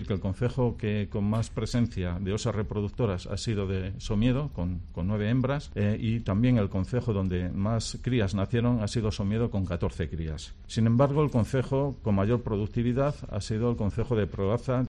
El director general de Biodiversidad del Principado, sobre el aumento de osos pardo en Asturias